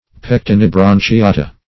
Search Result for " pectinibranchiata" : The Collaborative International Dictionary of English v.0.48: Pectinibranchiata \Pec`ti*ni*bran`chi*a"ta\, n. pl.